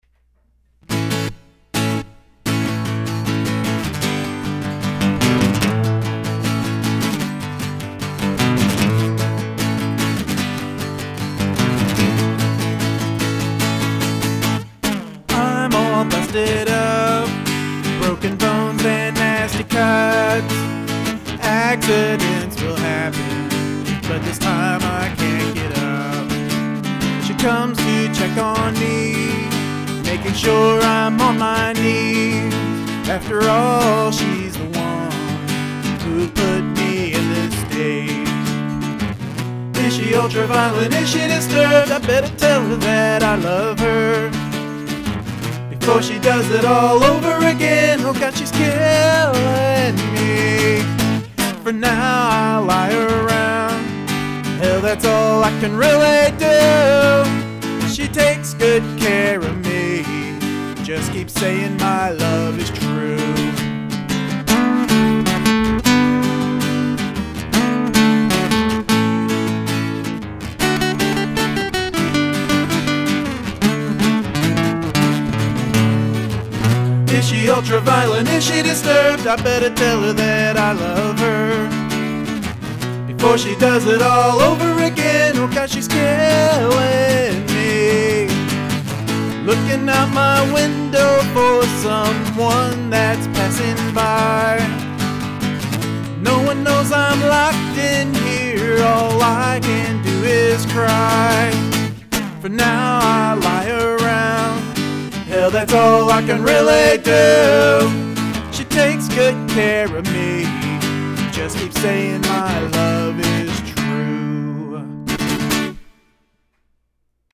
Gotta love the "one-takers" and the Punk Rock work ethic!
Nice playing and singing - I luv that backing vocal !!